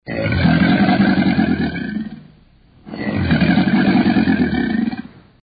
Звуки крокодила, аллигатора